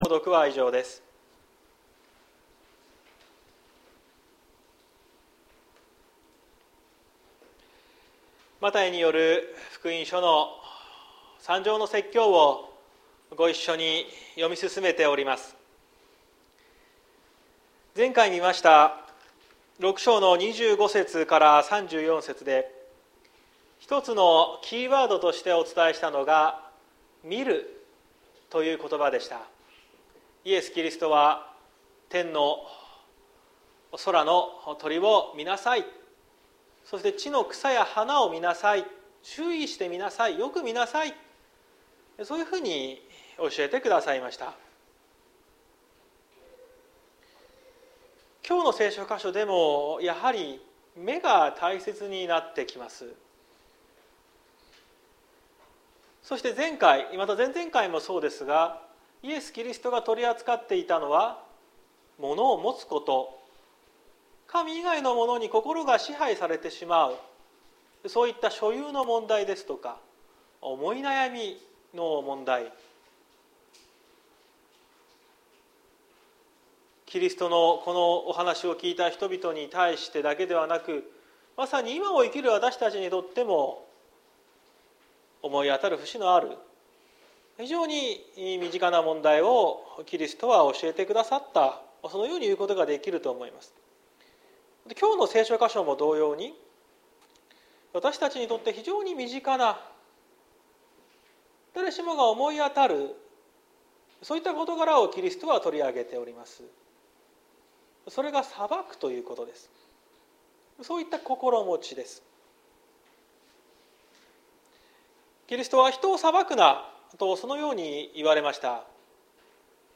2023年02月19日朝の礼拝「一億総評論家時代を生き抜く知恵」綱島教会
説教アーカイブ。